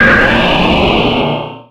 Cri de Méga-Laggron dans Pokémon Rubis Oméga et Saphir Alpha.
Cri_0260_Méga_ROSA.ogg